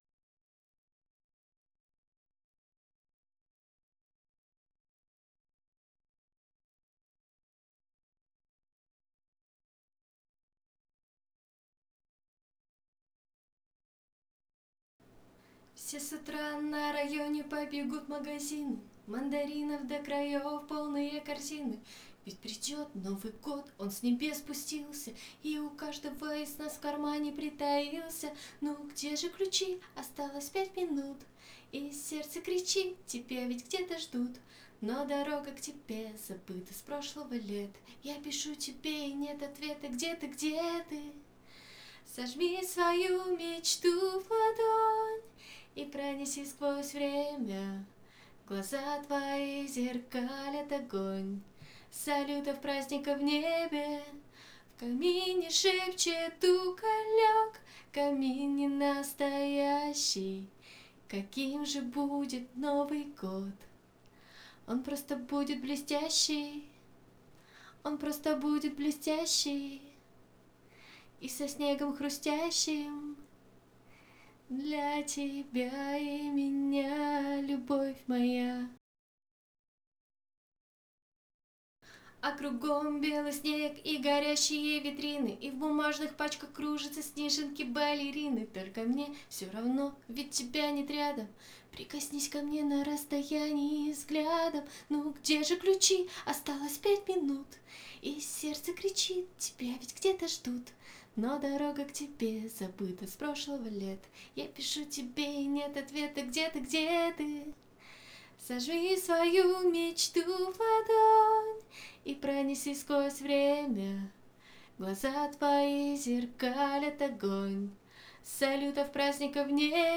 Здравствуйте! Купила звук карту Steinberg ur22 mkII , и микрофон AKG p120, первый раз когда записывала, все звучало вроде как хорошо, файлик тест микрофона...